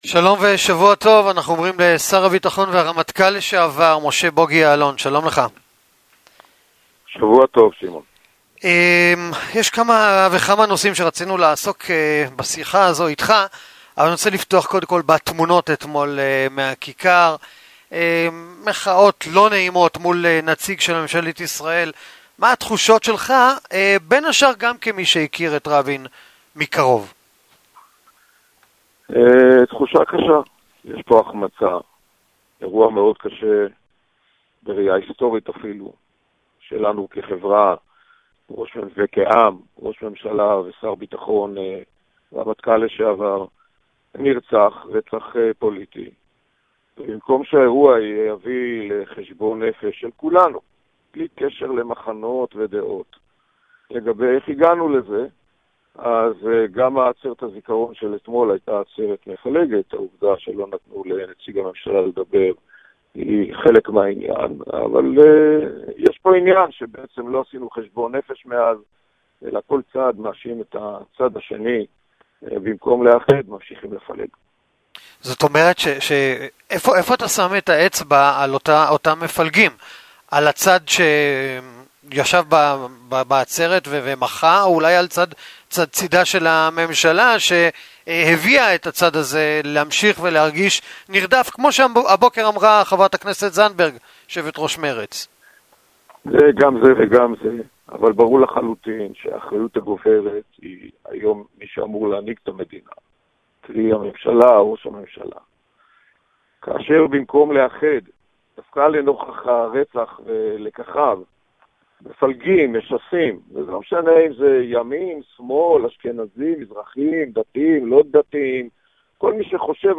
В интервью корреспонденту 7 канала , бывший министр обороны Моше (Буги) Яалон выразил своё отношение к действиям правительства, санкциям, наложенным США на Иран, к возможности возвращения тел Адара Голдина и Орона шауляя, а также поделился своими политическими планами в свете предстоящих выборов.